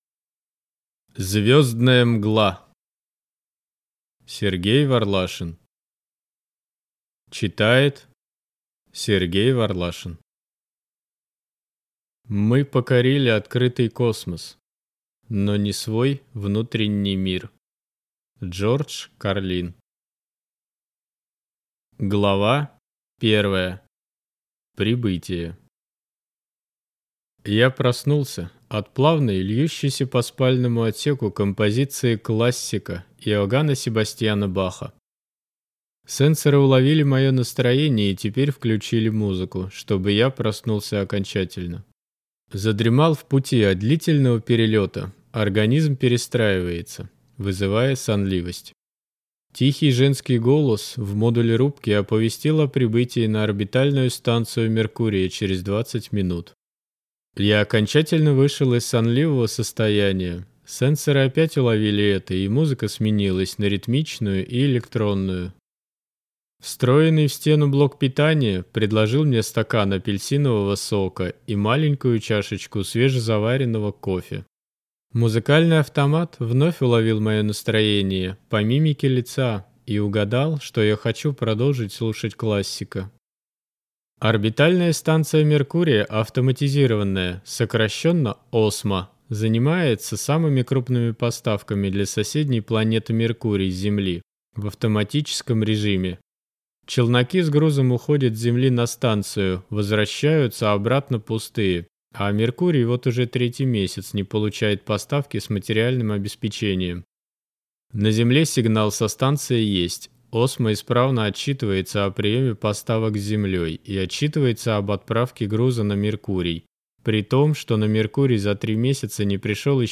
Аудиокнига Звездная МгЛА | Библиотека аудиокниг